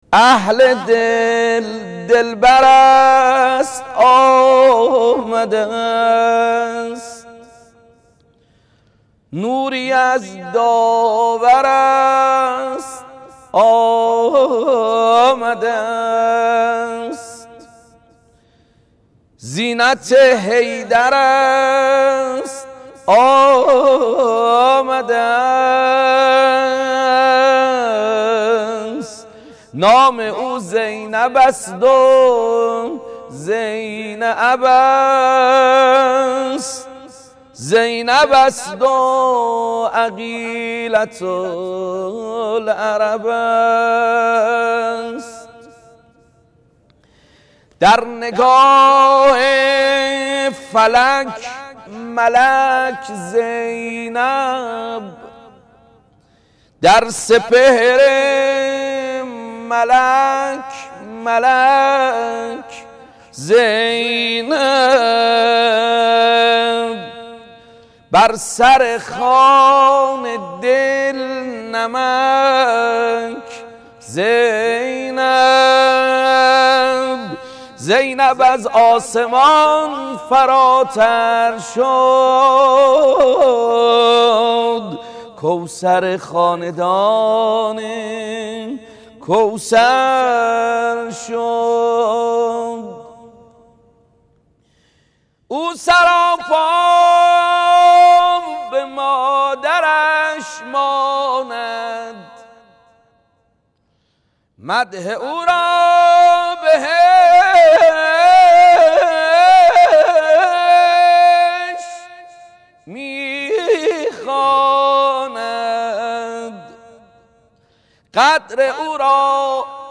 مدیحه خوانی
بمناسبت ایام ولادت حضرت زینب کبری (سلام الله علیهــا)